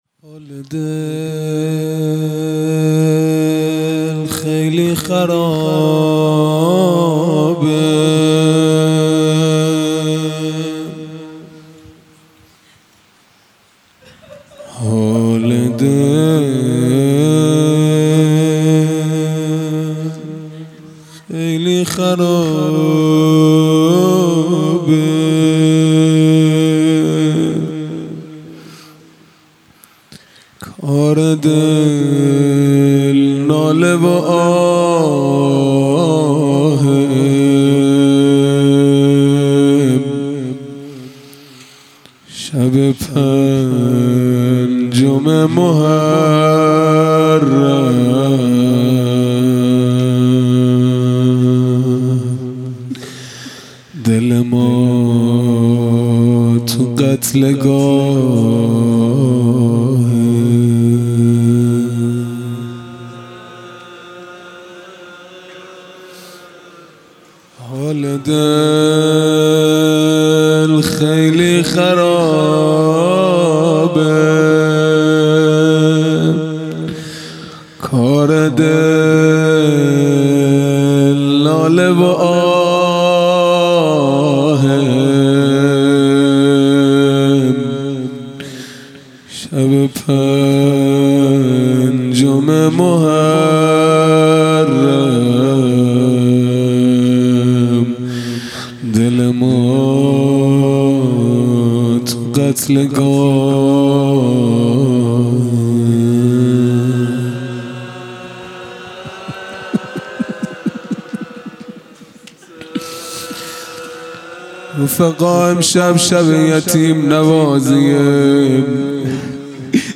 خیمه گاه - هیئت بچه های فاطمه (س) - روضه | حال دل خیلی خرابه
محرم ۱۴۴۱ |‌ شب پنجم